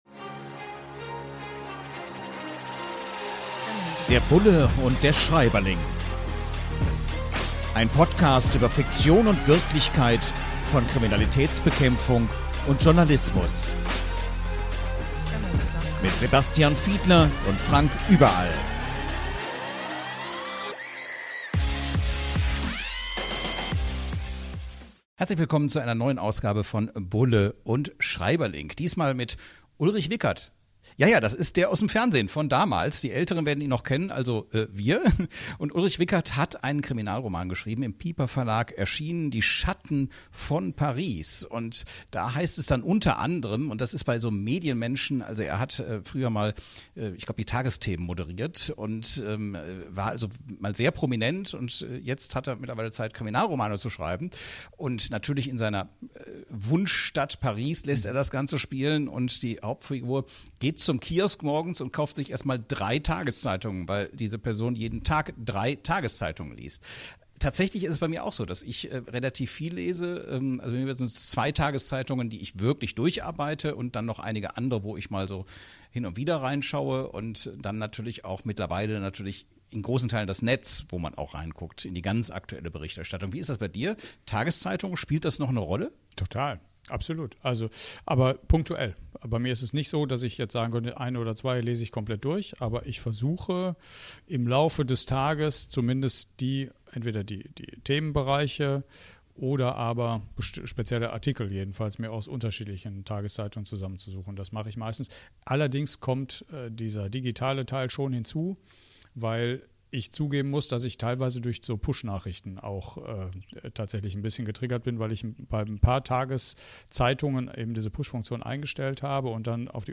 In launiger Atmosphäre tauschen sich die Beiden anhand von Aspekten aus, die in dem Buch beschrieben werden.